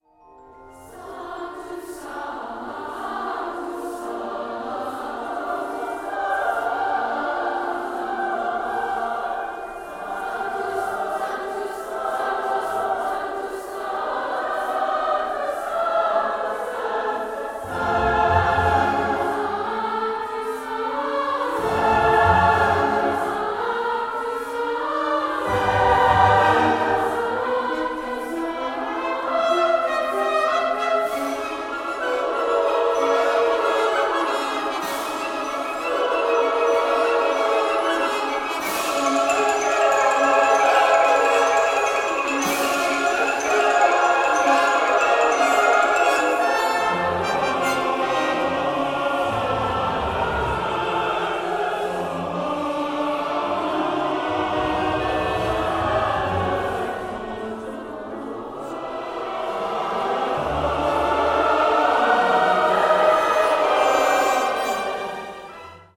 organ
Symphony No 1 in D minor
Allegro moderato